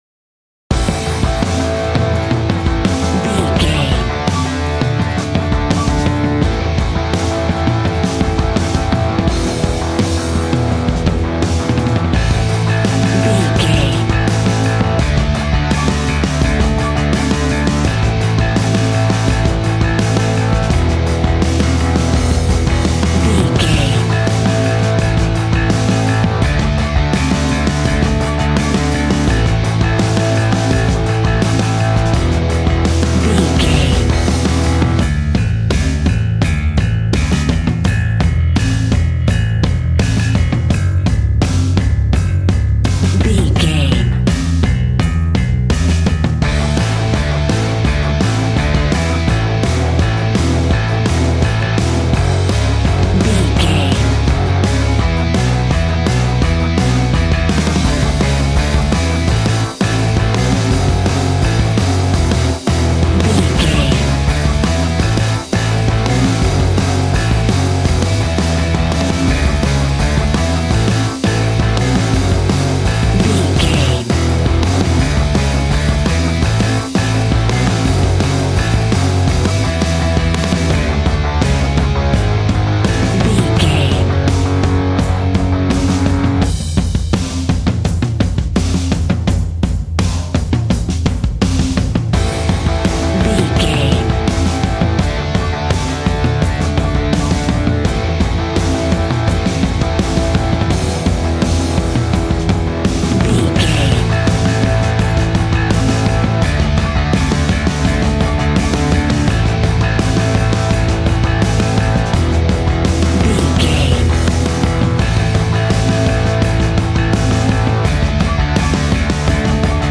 Aeolian/Minor
G♭
groovy
powerful
electric organ
drums
electric guitar
bass guitar